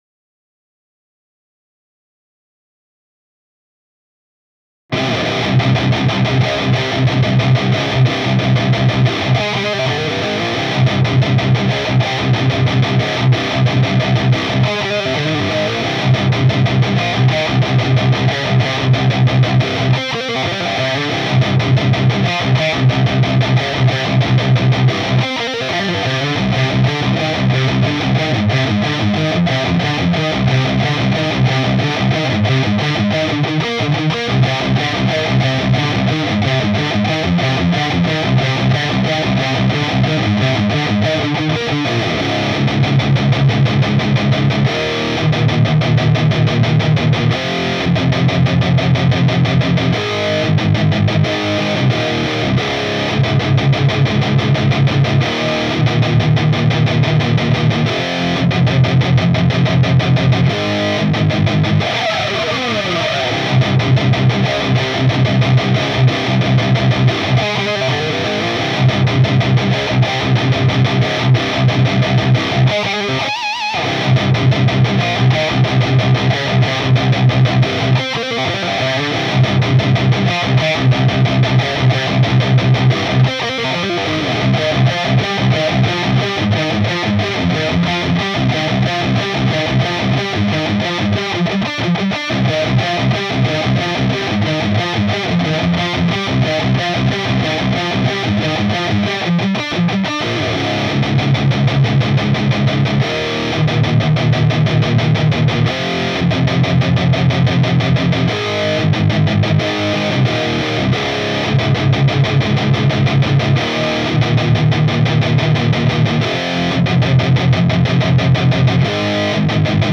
Si tu mets le même son avec un autre micro genre statique à grosse capsule, ta perception du niveau de gain va changer.
REAMP GT L1 AT 4050.wav